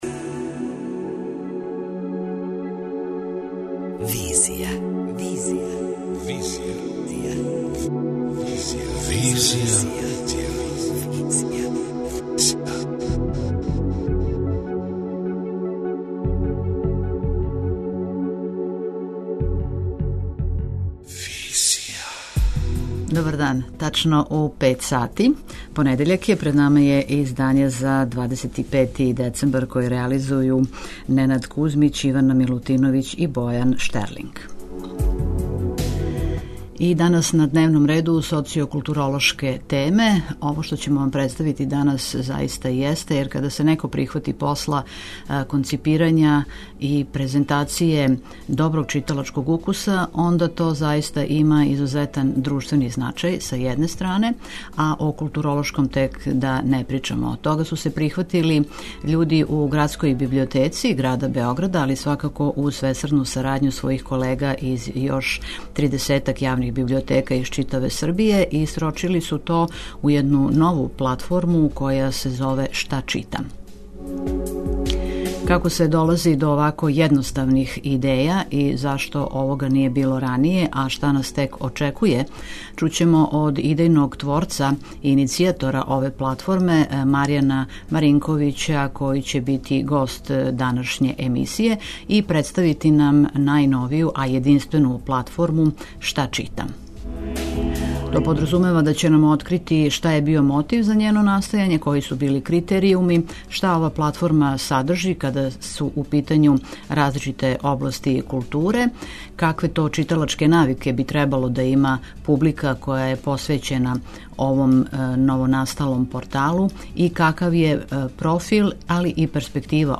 преузми : 27.53 MB Визија Autor: Београд 202 Социо-културолошки магазин, који прати савремене друштвене феномене.